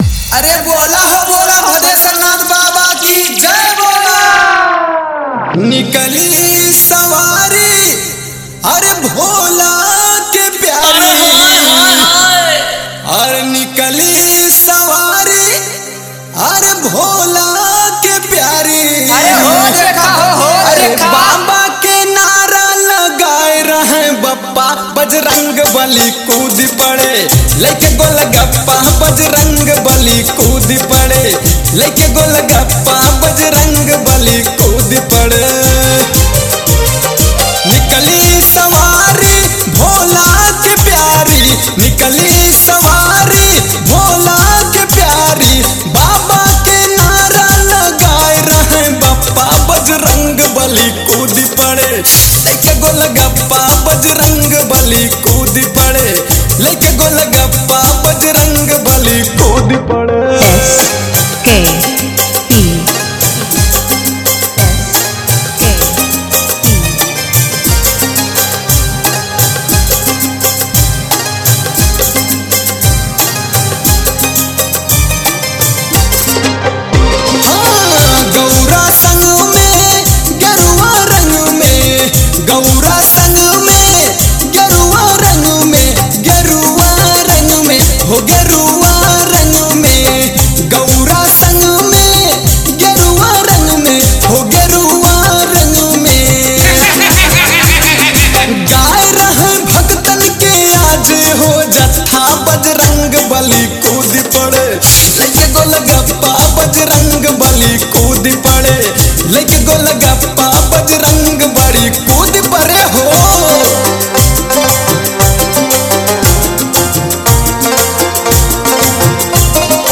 Bolbam 2025 Dj Songs Report This Song Play Pause Vol + Vol -